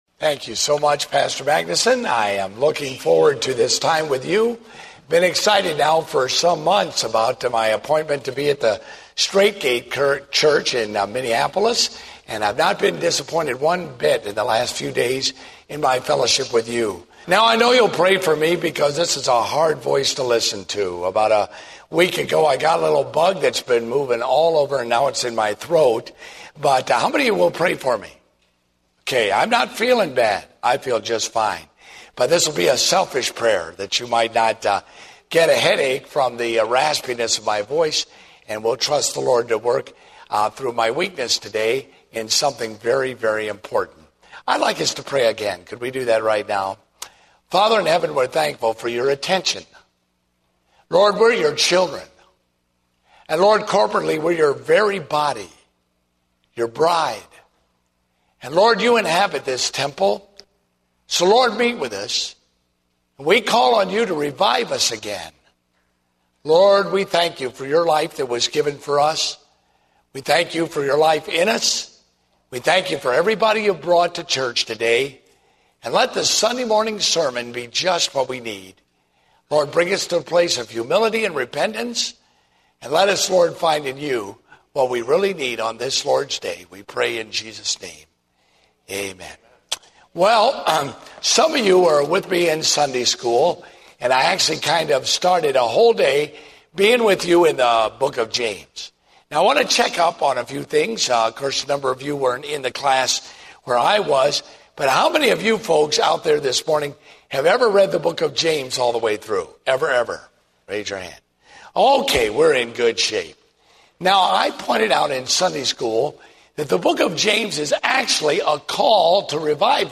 Date: August 29, 2010 (Morning Service)